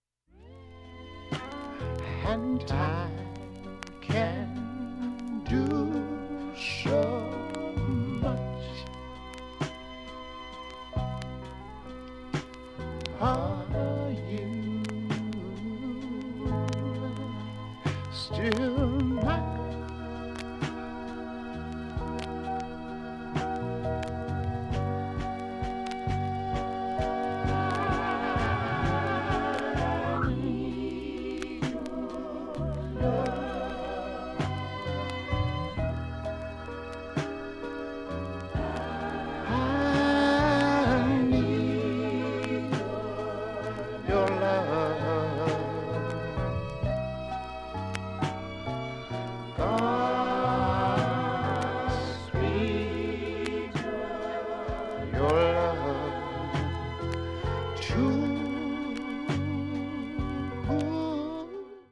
盤面きれいで音質良好全曲試聴済み。
６０秒の間に周回プツ出ますがかすかです。